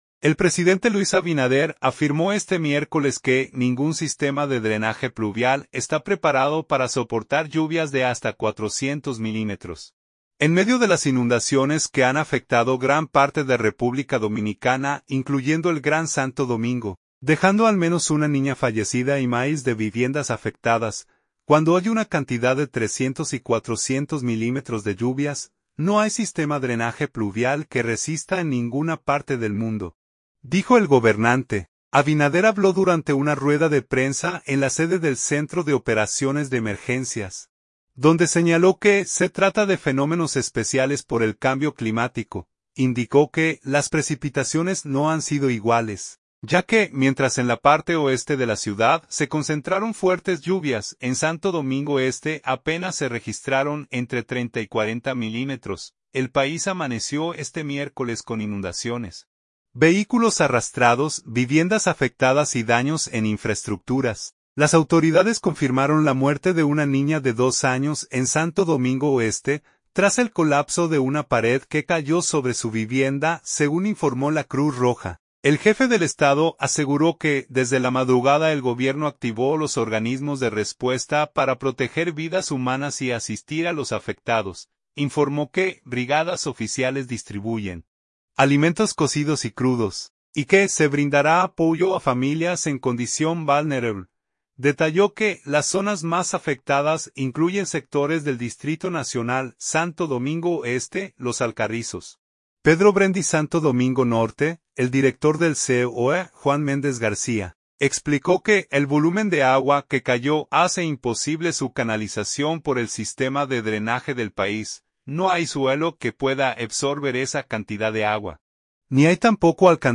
Abinader habló durante una rueda de prensa en la sede del Centro de Operaciones de Emergencias, donde señaló que se trata de fenómenos “especiales” por el cambio climático.